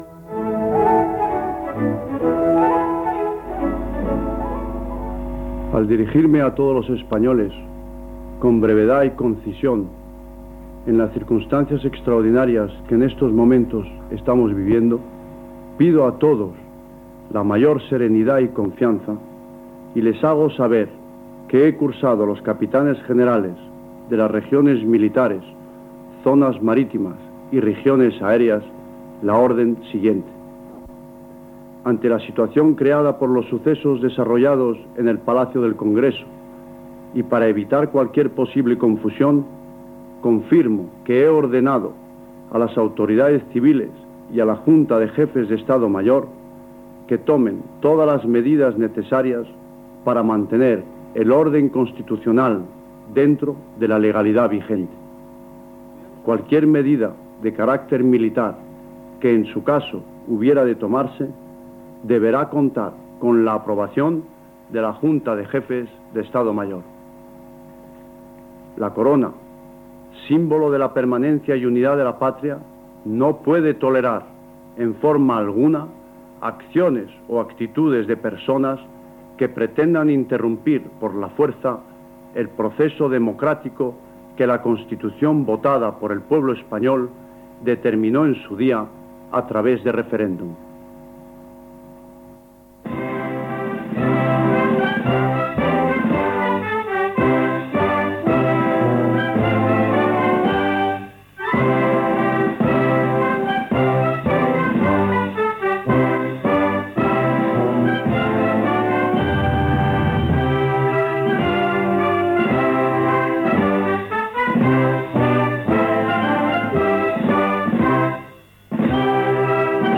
Discurs del rei Juan Carlos I, la matinada següent a l'intent de cop d'estat militar de la tarda del 23 de febrer.
Informatiu